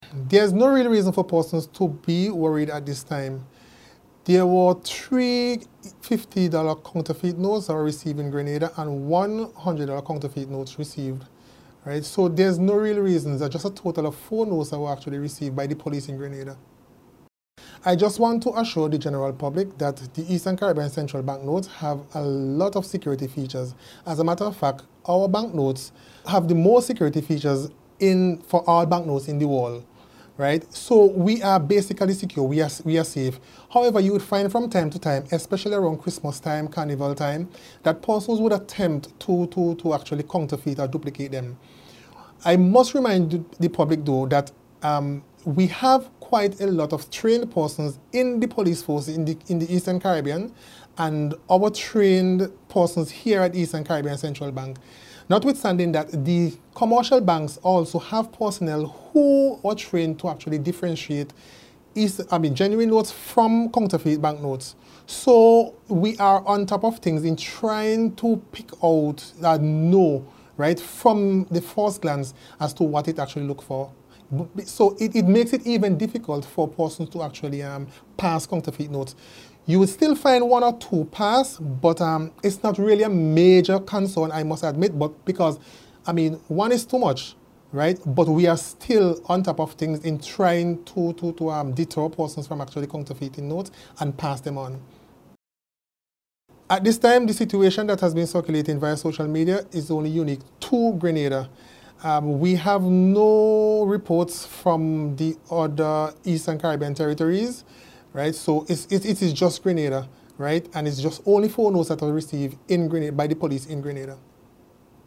Statement-on-Counterfeit-Notes.mp3